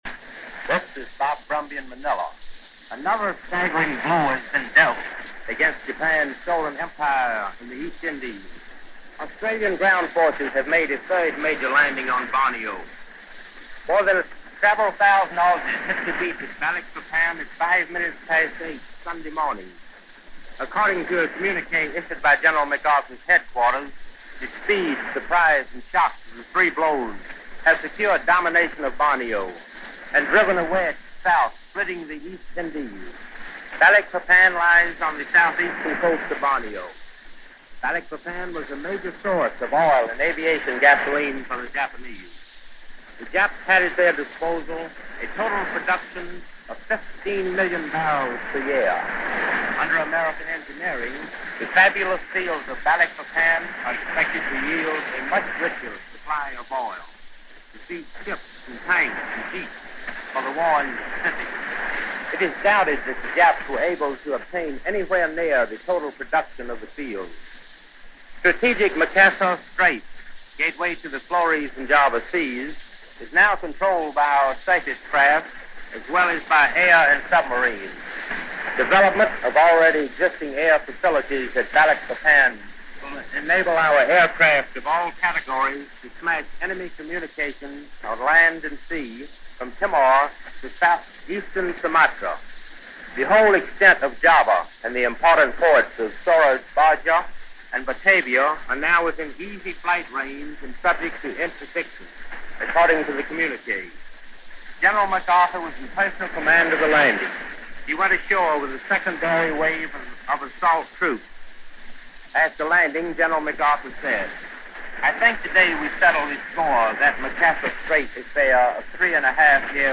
Here are two reports from Mutual Correspondents went ashore during the first wave of the allied invasion force on July 1, 1945